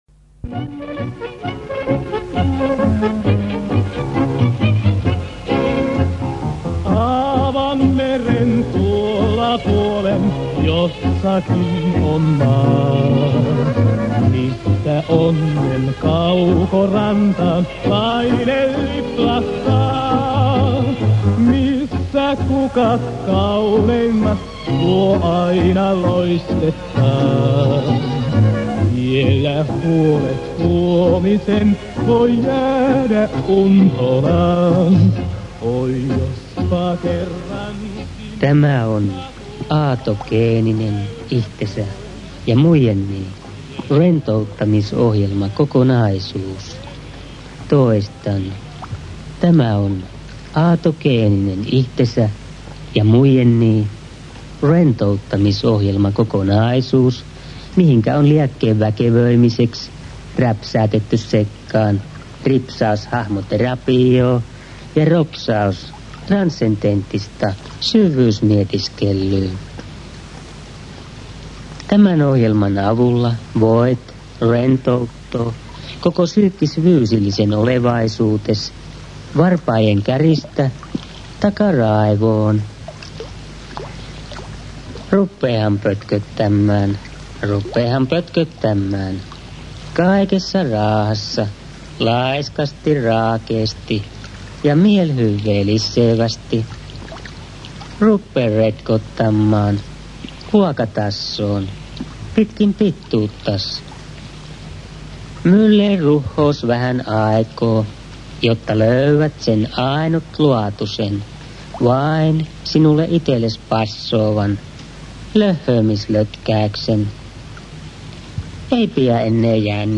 Savolainen rentoutus